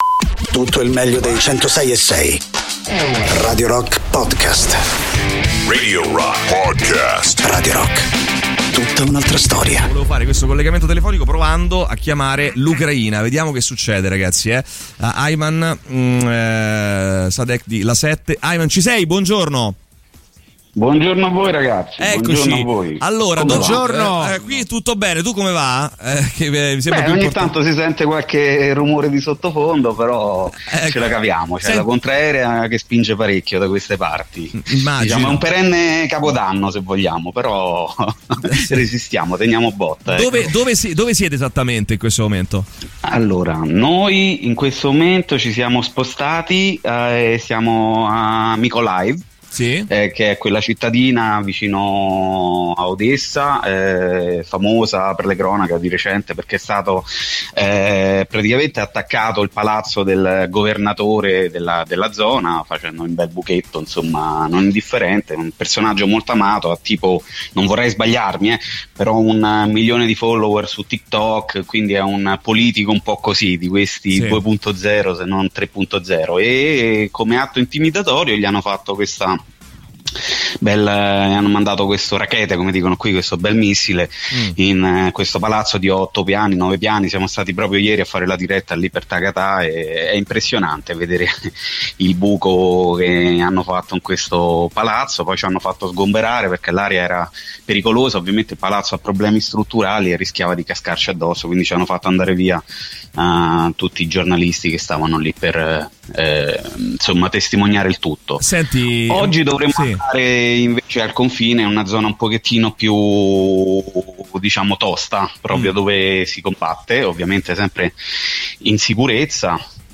The Rock Show: Intervista